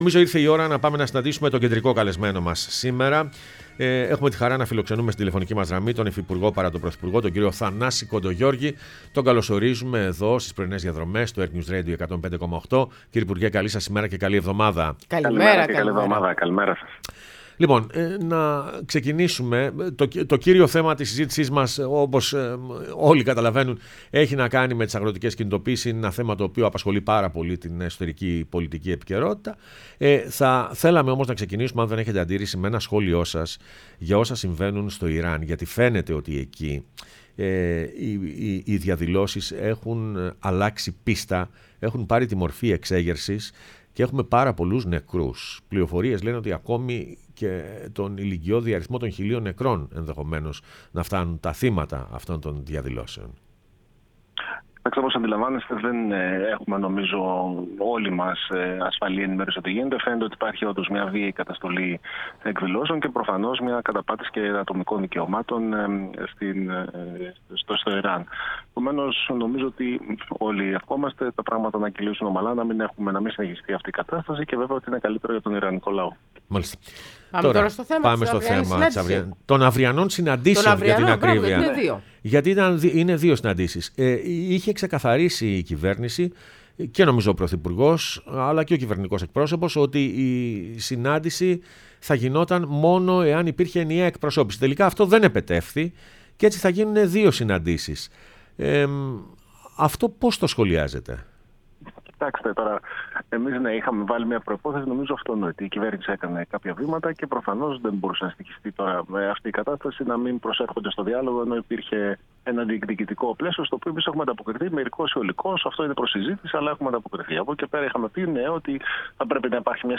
Θανάσης Κοντογεώργης, Υφυπουργός παρά τω Πρωθυπουργώ, μίλησε στην εκπομπή «Πρωινές Διαδρομές»